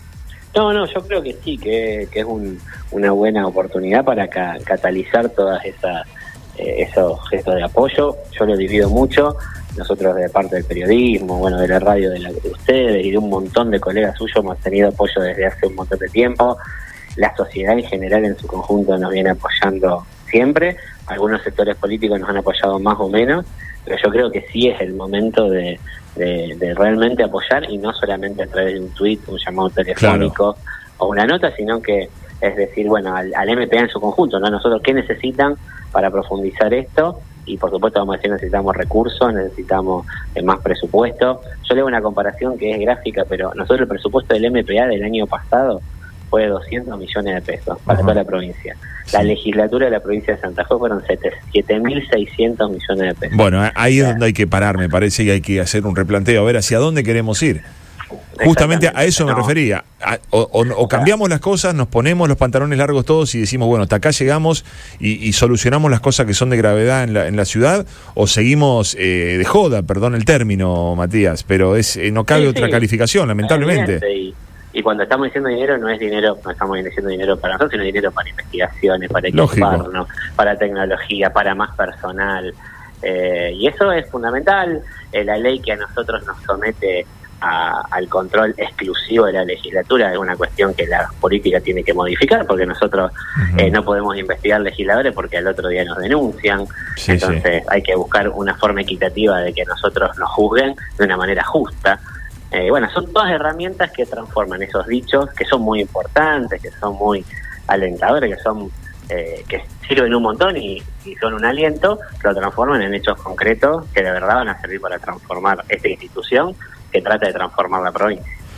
EN RADIO BOING